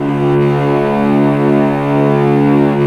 STR ARCOCL00.wav